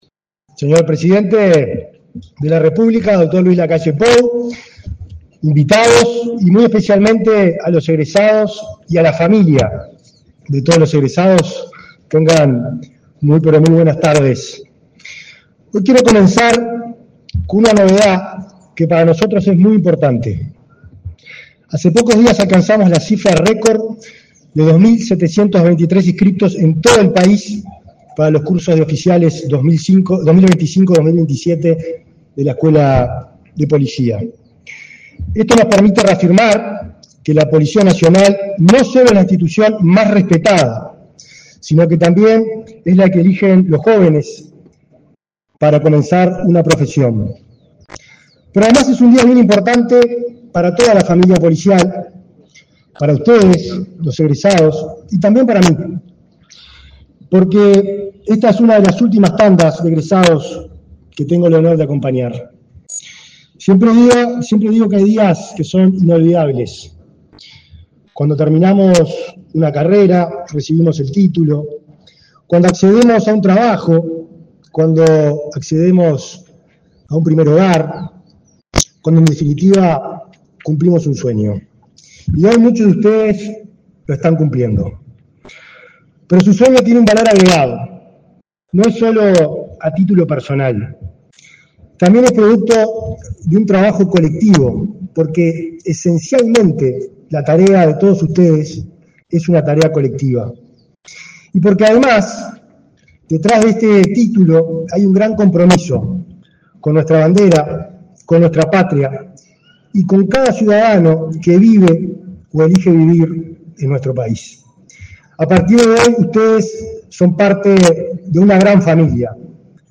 Palabras del ministro del Interior, Nicolás Martinelli
El presidente de la República, Luis Lacalle Pou, participó, este 12 de diciembre, en la ceremonia de egreso de oficiales ayudantes de la Policía
En el evento disertó el ministro del Interior, Nicolás Martinelli.